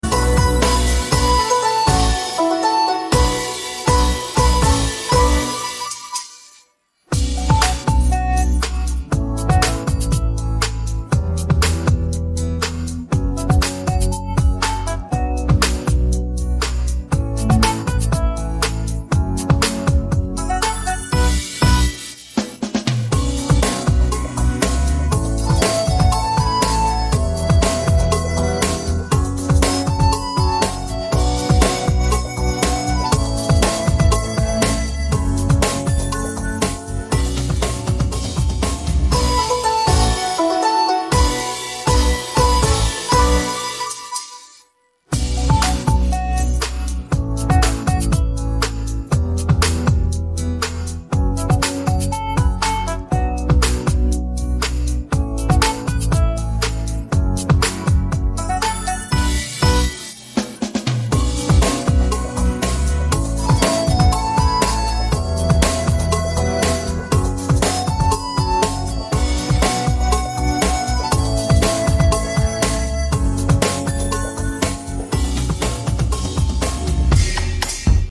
Baggrundsmusik